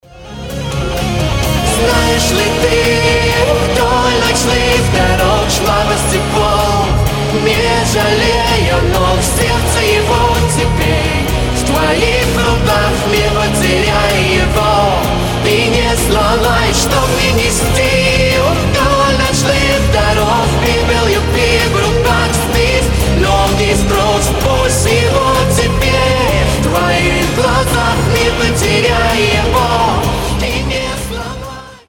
рок , романтические , mashup